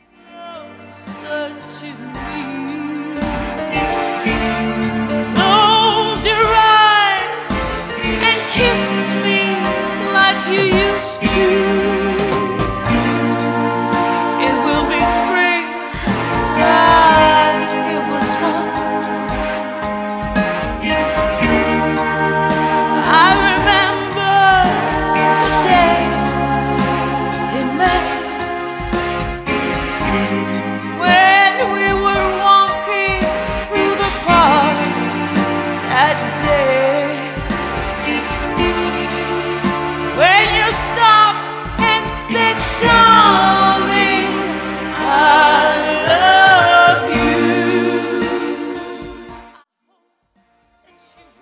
Enregistrement, Studio
Guitares: Électrique / Acoustique
Piano / Orgue
Choriste